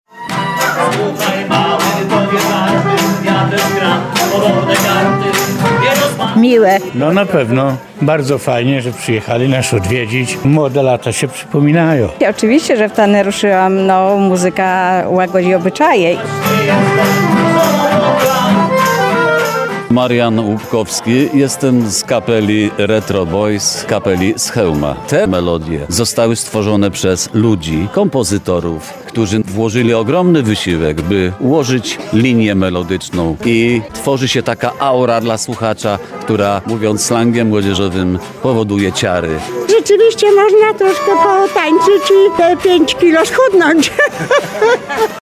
Muzyka na żywo, spontaniczne potańcówki i klimat w stylu retro - w Łęcznej trwa XXVI Festiwal Kapel Ulicznych i Podwórkowych.